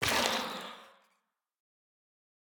Minecraft Version Minecraft Version latest Latest Release | Latest Snapshot latest / assets / minecraft / sounds / block / sculk_shrieker / break5.ogg Compare With Compare With Latest Release | Latest Snapshot